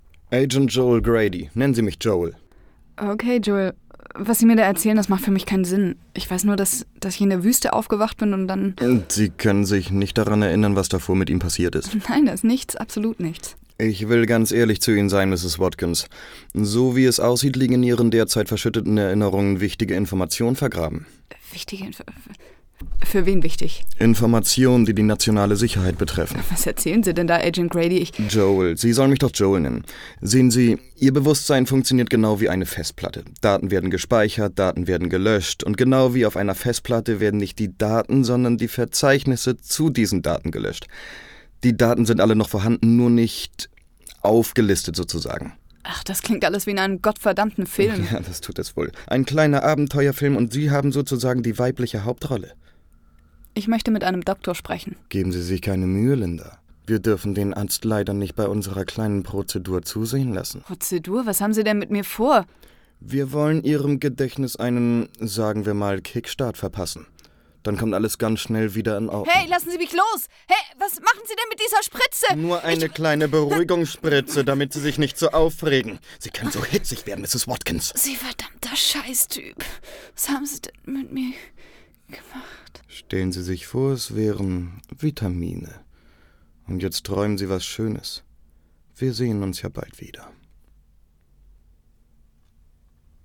lebendig, kräftig, ruhig, variabel, Trickfilm, Comic, jung, dynamisch, frech
schwäbisch
Sprechprobe: eLearning (Muttersprache):